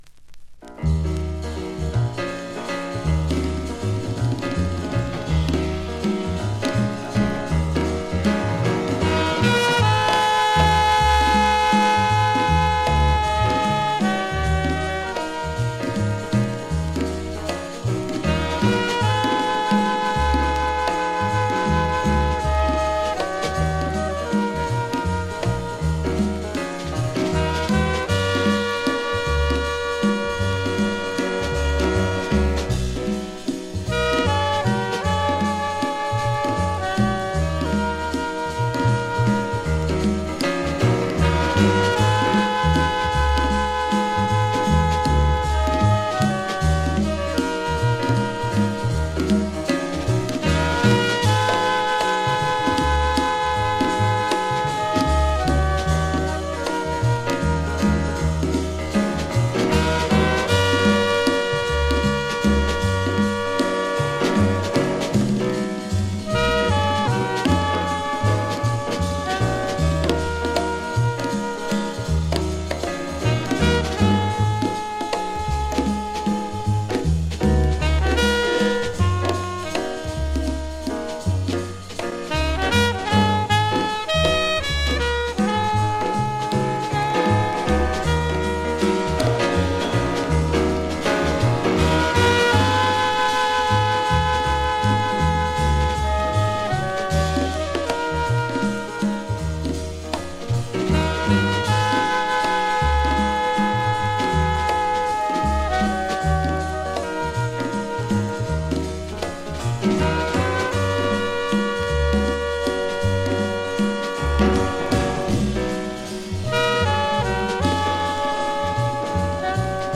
エモーショナルなワルツ